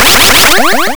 bfxr_LifeExtend.wav